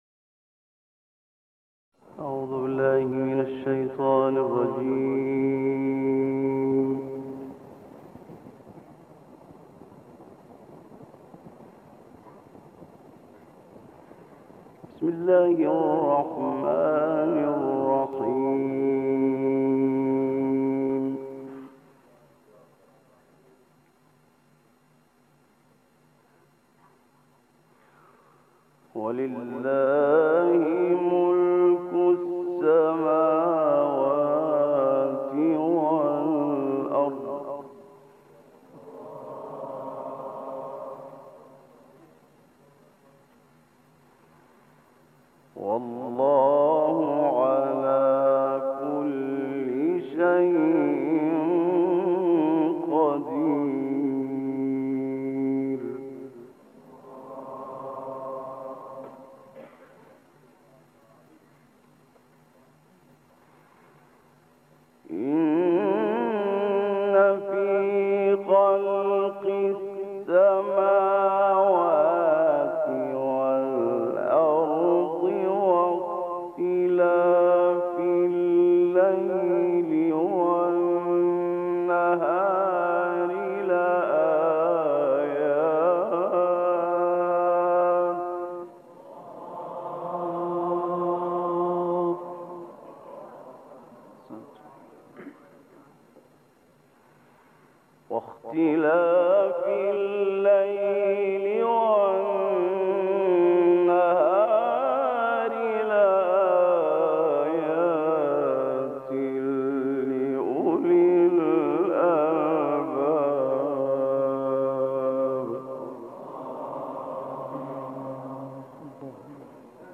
تلاوت آیات ۱۹۵-۱۸۹ آل‌ عمران با صدای محمد اللیثی + دانلود
گروه فعالیت‌های قرآنی: خبرگزاری ایکنا با همکاری شبکه رادیویی قرآن، قطعه‌ای دلنشین از تلاوت محمد اللیثی از آیات ۱۹۵-۱۸۹ سوره آل‌عمران را ارائه می‌دهد.